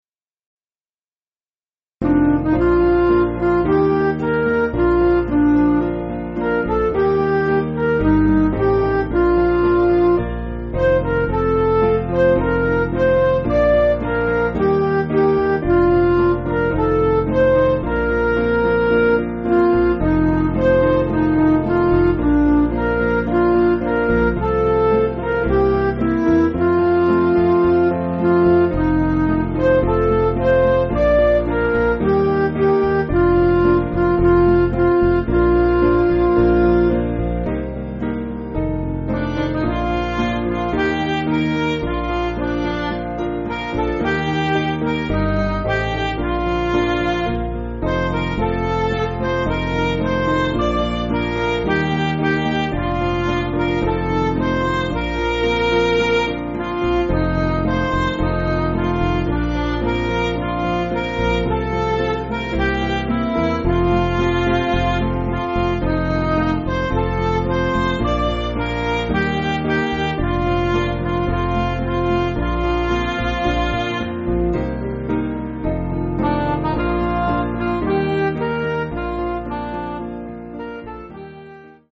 (CM)   4/Bb
Midi